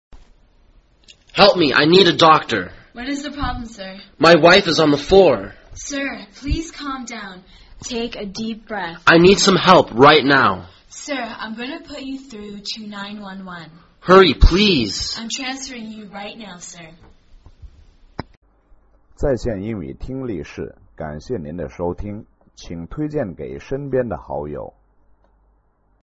旅馆英语对话-Medical Emergency(2) 听力文件下载—在线英语听力室